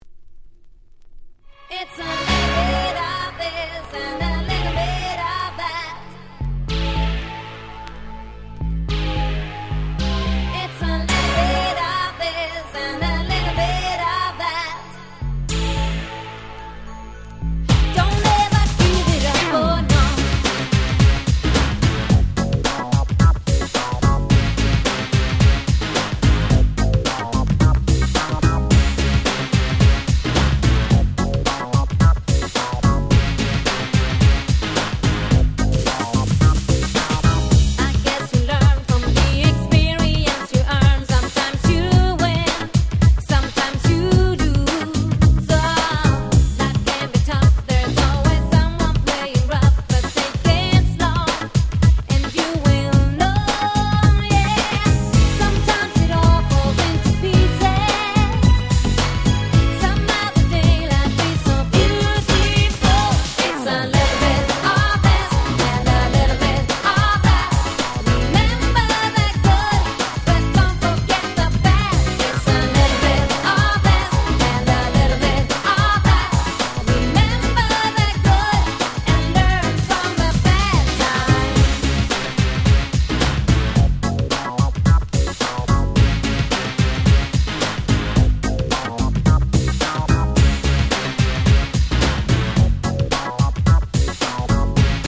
この辺のRagga Pop物、キャッチーでやっぱり最高ですよね。